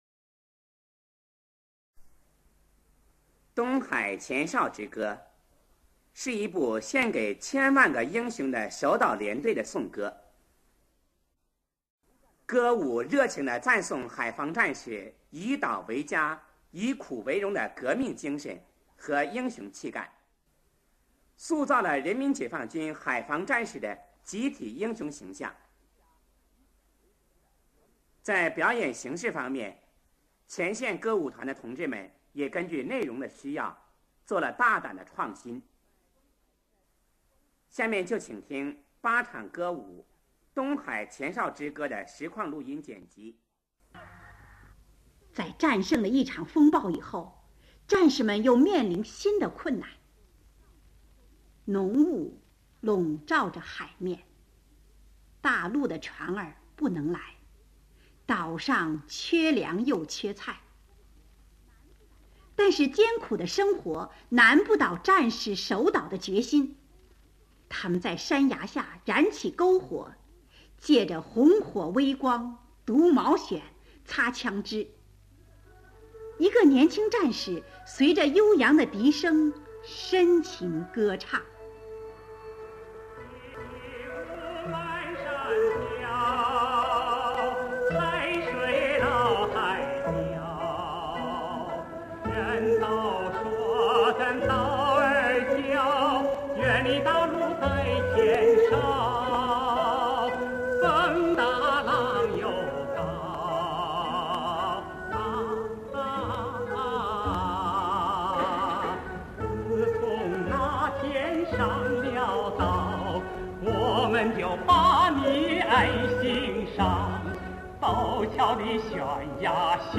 上海人民广播电台进行了实况录音
独唱歌曲《战士的第二个故乡》出现在第四场。